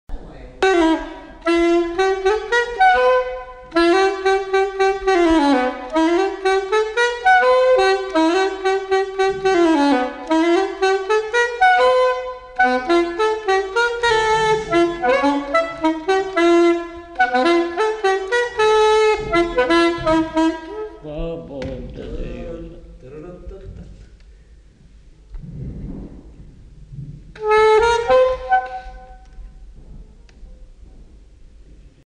Scottish
Aire culturelle : Haut-Agenais
Lieu : Lougratte
Genre : morceau instrumental
Instrument de musique : saxophone
Danse : scottish